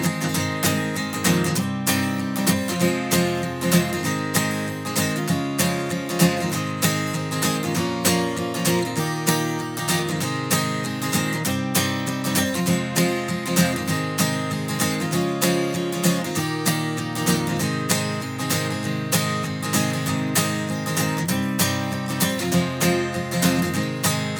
navy_acousticguitar.flac